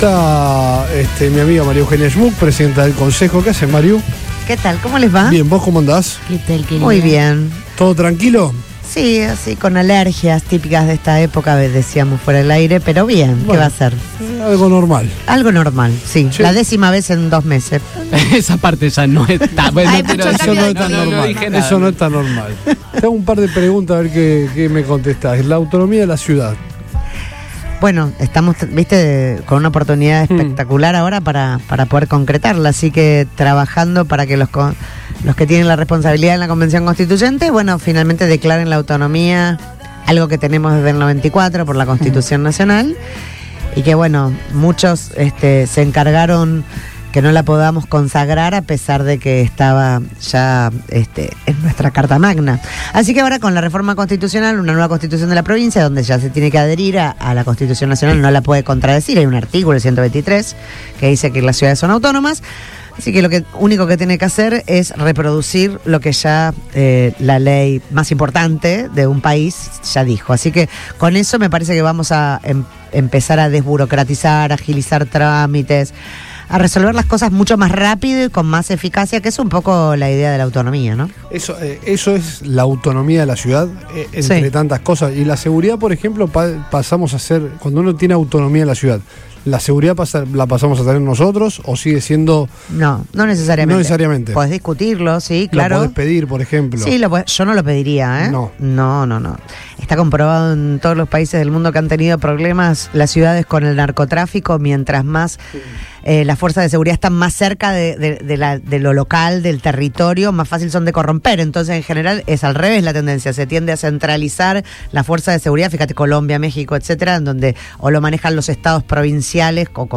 La presidenta del Concejo Municipal, María Eugenia Schmuck visitó los estudios de Radio Boing y conversó con el equipo de Todo Pasa turno tarde.